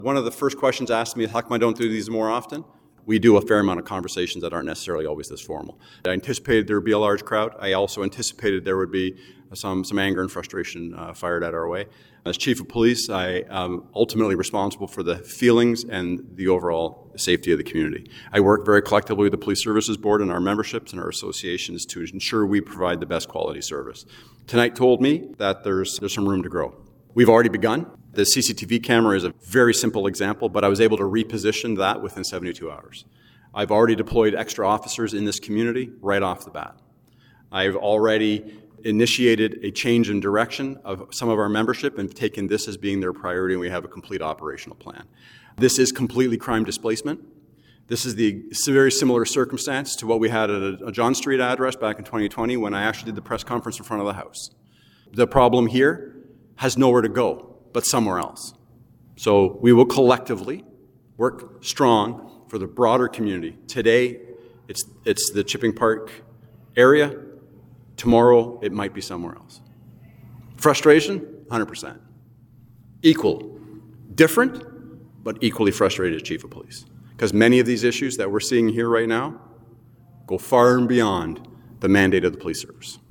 It was standing room only Thursday night at the Salvation Army on Ballentine Street as Cobourg Police hosted a neighborhood meeting for the residents of the Chipping Park Boulevard Area to address localized concerns.